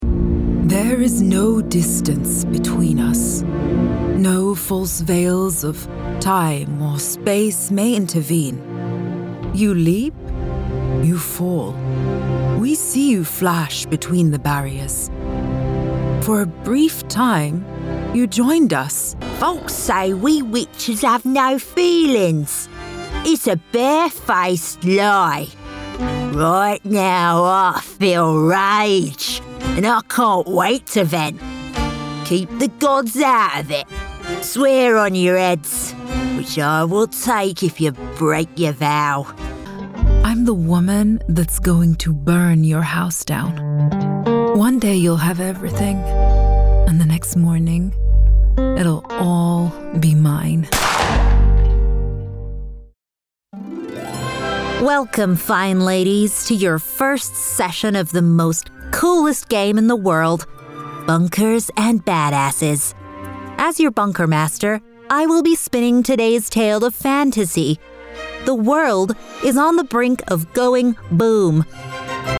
Profonde, Naturelle, Distinctive, Chaude, Douce